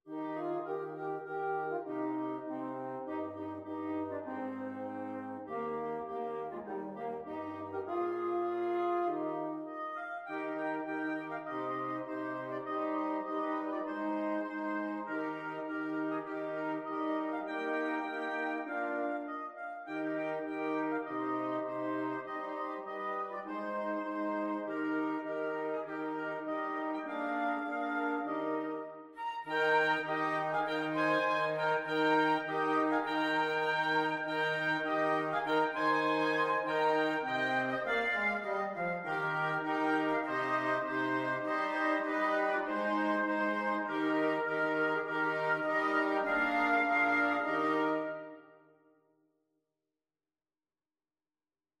FluteOboeClarinetFrench HornBassoon
4/4 (View more 4/4 Music)
Moderato
Wind Quintet  (View more Easy Wind Quintet Music)
Traditional (View more Traditional Wind Quintet Music)